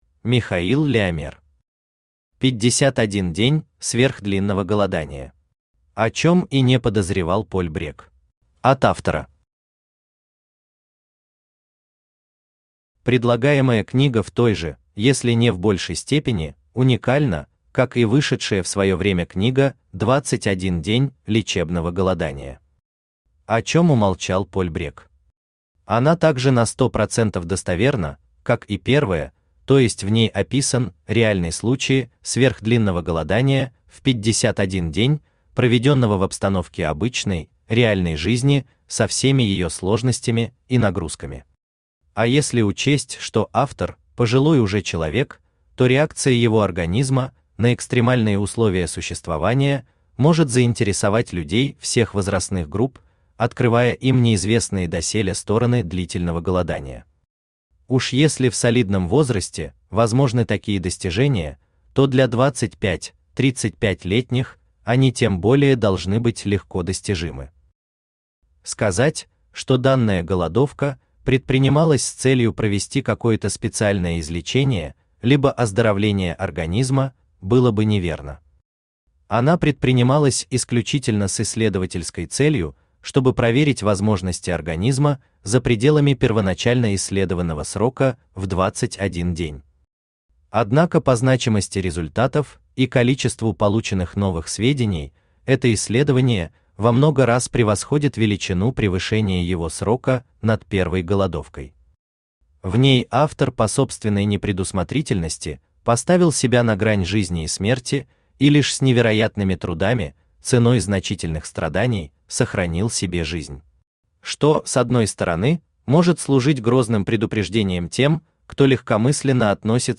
Аудиокнига 51 день сверхдлинного голодания. О чём и не подозревал Поль Брегг | Библиотека аудиокниг
О чём и не подозревал Поль Брегг Автор Михаил Леомер Читает аудиокнигу Авточтец ЛитРес.